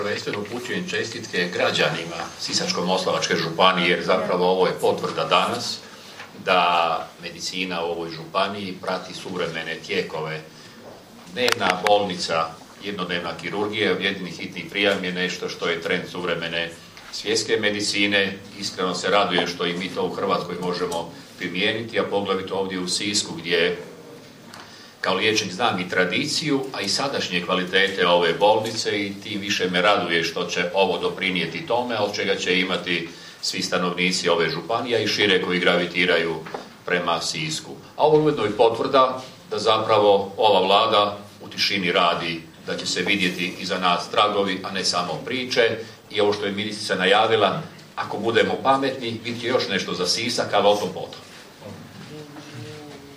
Ministar zdravstva Milan Kujundžić: